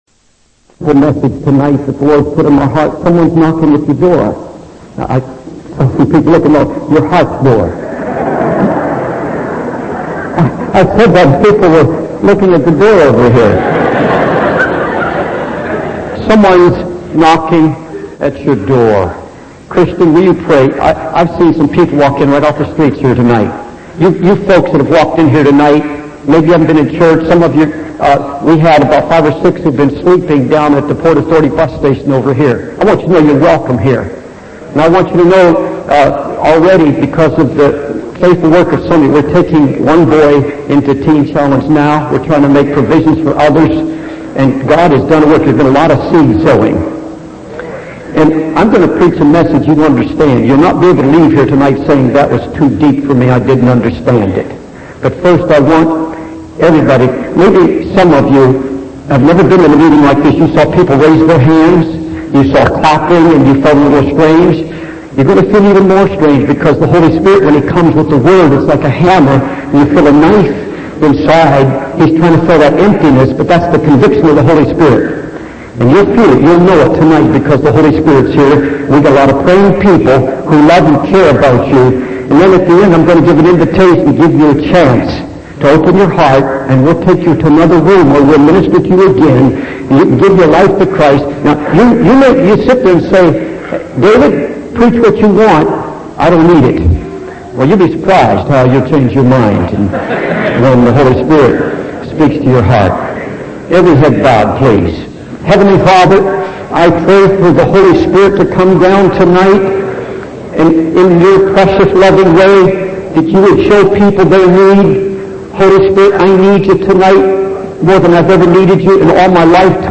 In this sermon, the preacher addresses the issue of profanity and the use of God's name in vain in everyday conversations.